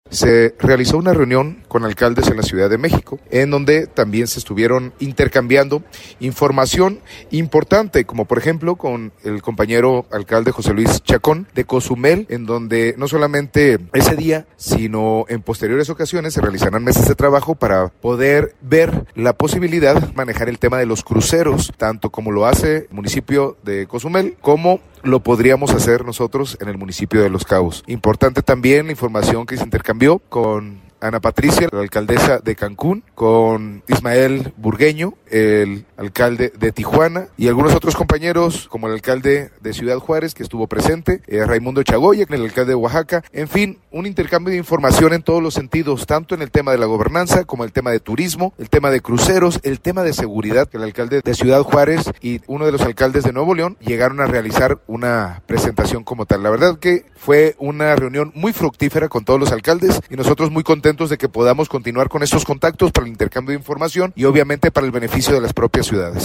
Christian Agúndez Gómez – Alcalde de Los Cabos
Insert-Christian-Agundez-Intercambio-CDMX.mp3